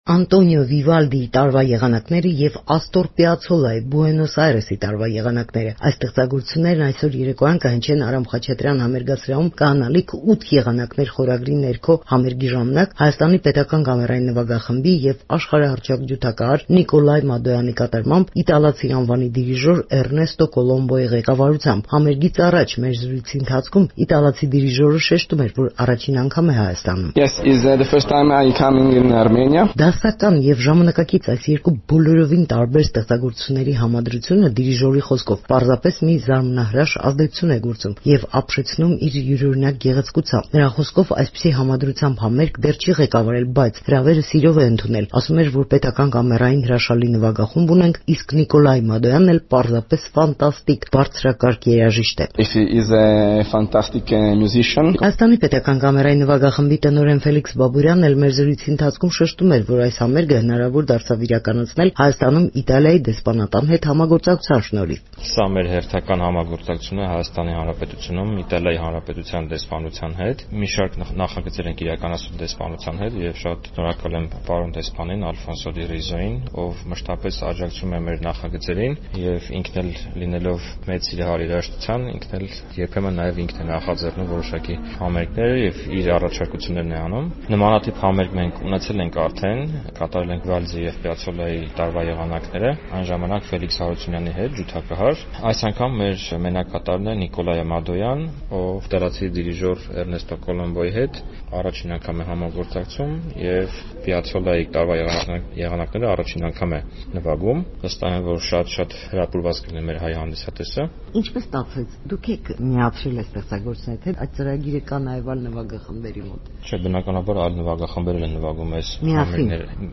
Վիվալդիի և Պիացոլա. «8 եղանակներ» խորագրի ներքո համերգ
Ռեպորտաժներ